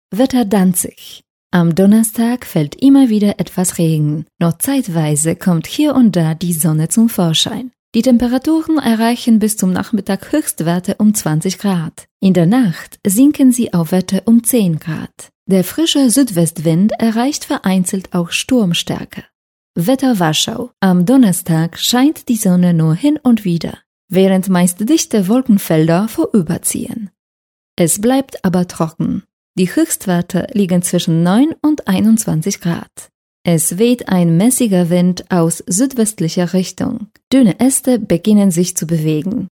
Female 30-50 lat
Nagranie lektorskie
wetter.mp3